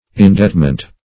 Indebtment \In*debt"ment\